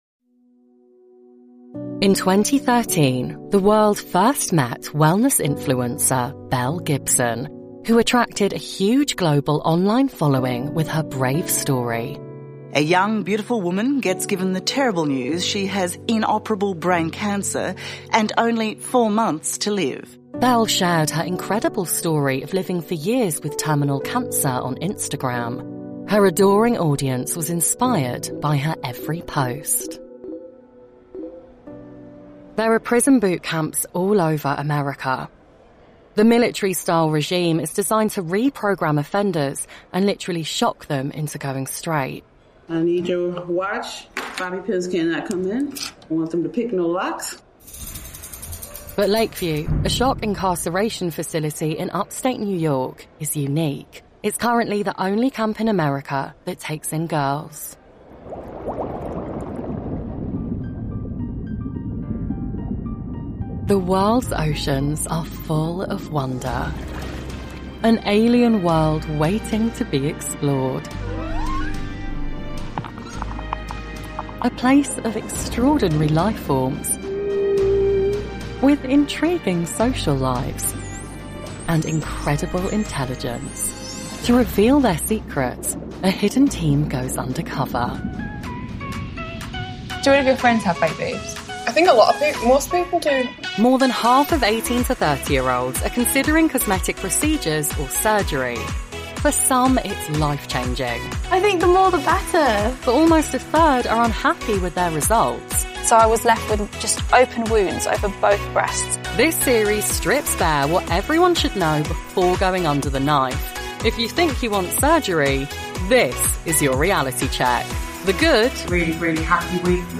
Dokumentarfilme
Mein natürlicher London-Estuary-Akzent, kombiniert mit einem sympathischen Millennial-Charme, ermöglicht es mir, mich nahtlos an verschiedene Projekte anzupassen.
Meine klare, gesprächige Stimme macht komplexe Themen zugänglicher und ansprechender und verwandelt selbst die banalsten Inhalte in etwas Spannendes und Unterhaltsames.
SessionBooth doppelwandige Gesangskabine mit Akustikpaneelen
Lewitt LCT 540 S Mikrofon